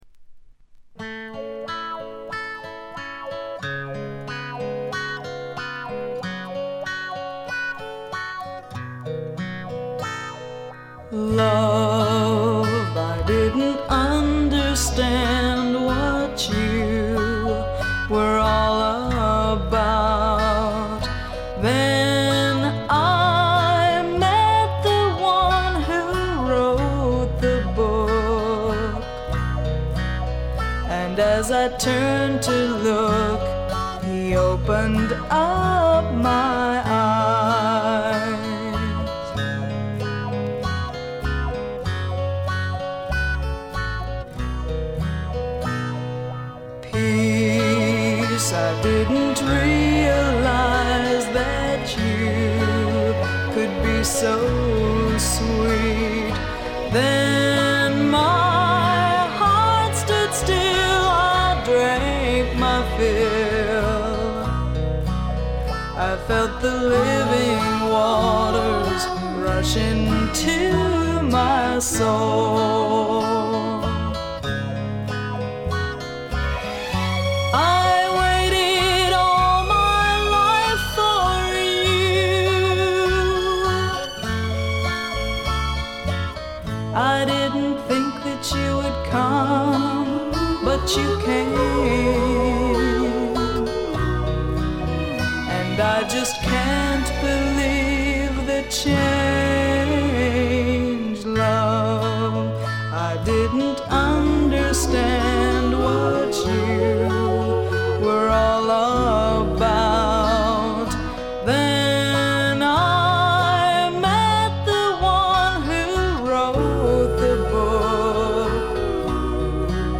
部分試聴ですがほとんどノイズ感無し。
クリスチャン・ミュージックの男女混成グループ
試聴曲は現品からの取り込み音源です。